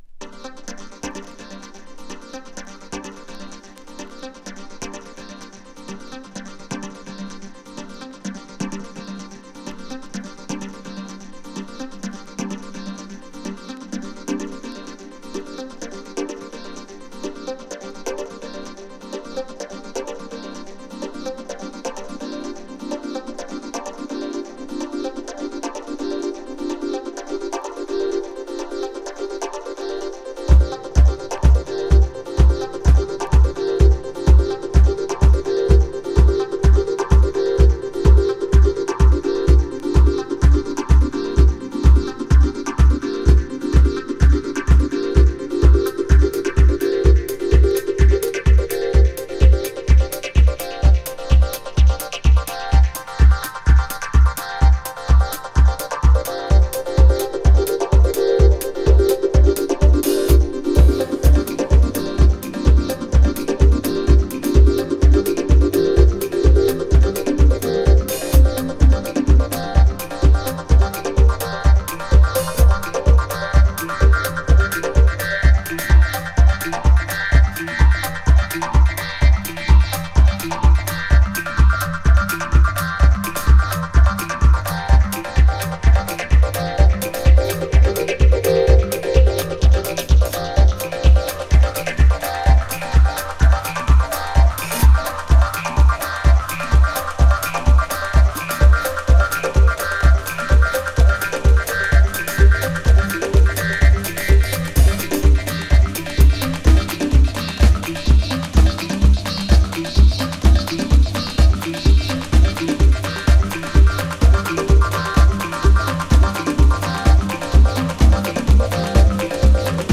南国感溢れるアルバムからの限定12インチ！！
トリップ感抜群のダンストラック